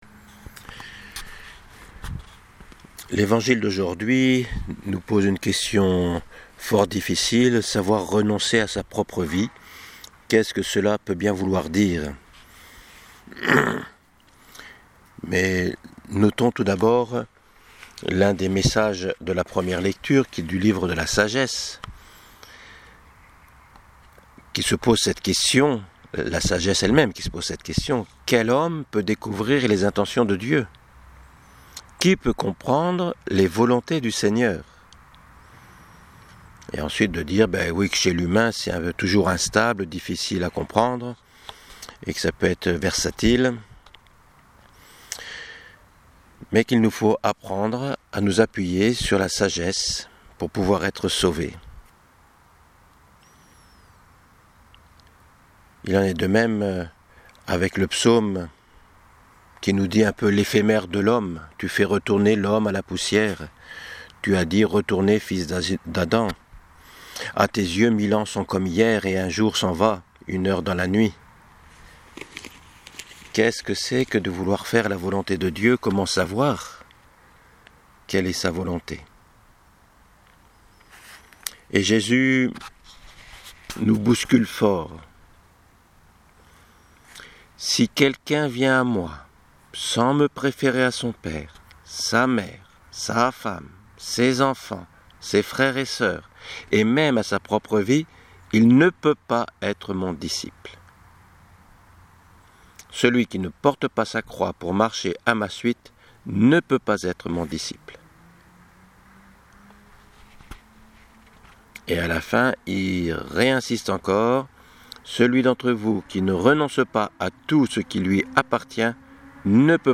Voici une double homélie à partir de ce XXII° dimanche du temps ordinaire.